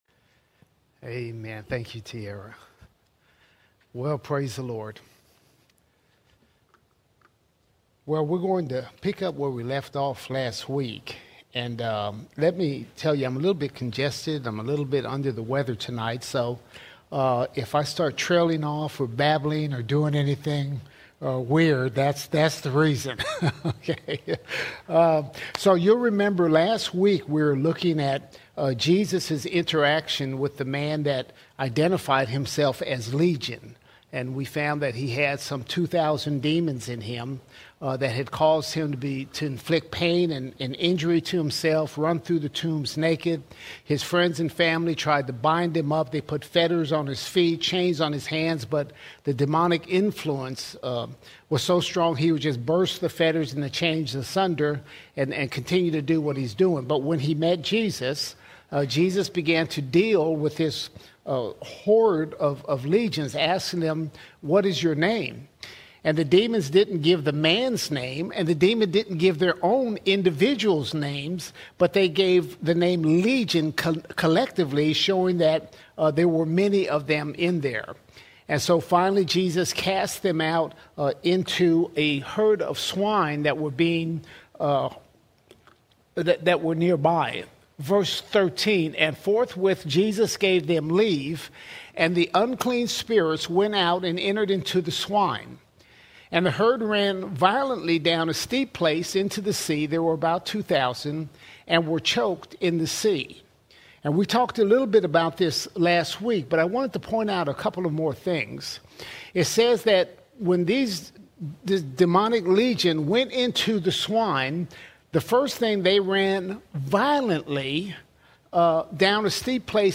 13 November 2025 Series: Gospel of Mark All Sermons Mark 5:14 - 5:40 Mark 5:14 – 5:40 A glimpse of Jesus’ unmatched authority!